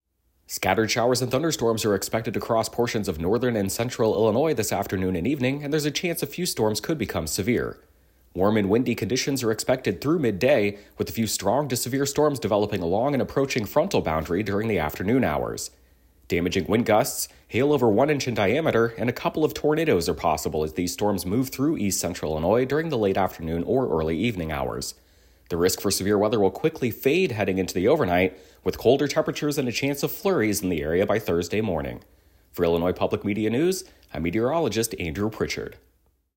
Below is his audio forecast from Wednesday morning.